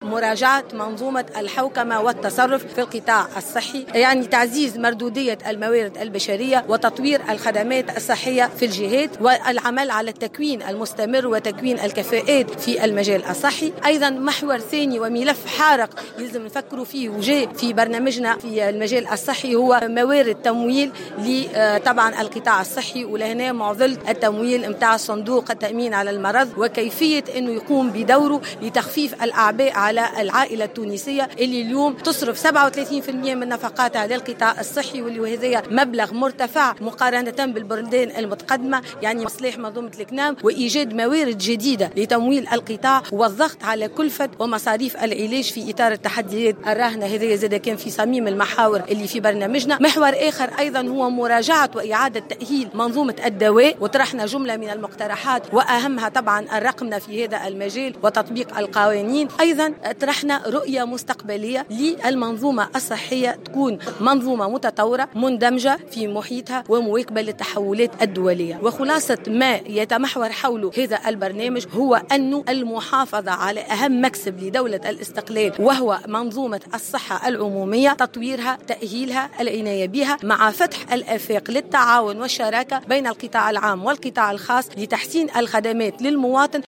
قدمت رئيسة الحزب الدستوري الحر عبير موسي، خلال ندوة صحفية، برنامج حزبها الانتخابي في القطاع الصحي.